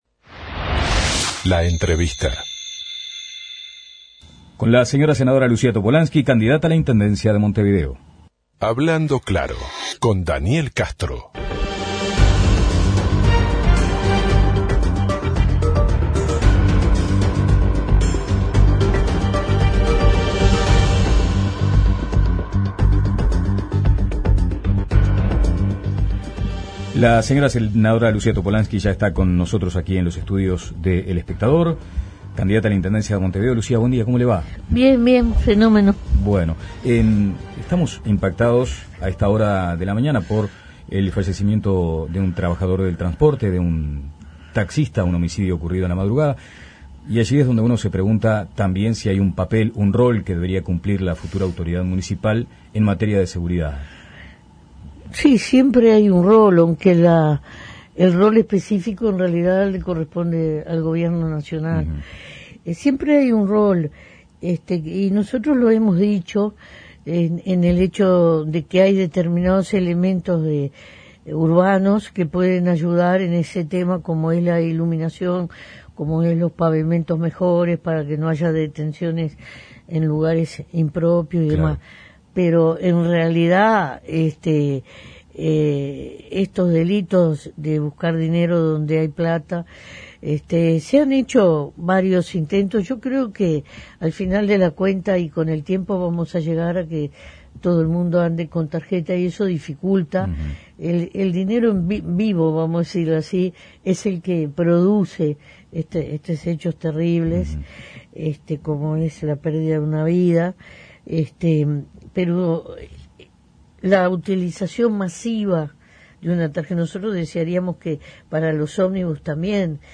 La senadora y candidata a la Intendencia de Montevideo, Lucía Topolansky, dijo a La Mañana de El Espectador que "la agredida" en esta campaña es ella y que nadie le ha pedido perdón.